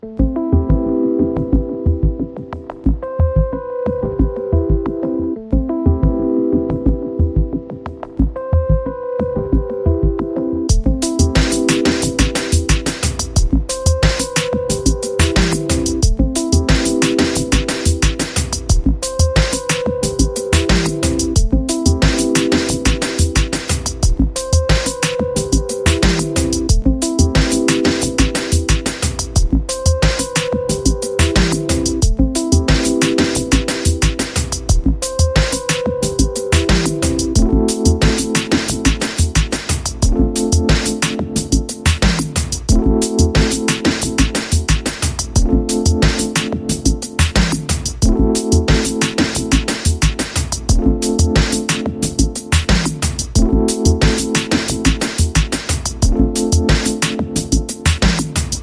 hold_tone.mp3